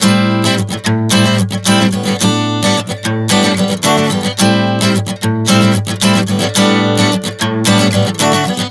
Prog 110 Am-A11-D.wav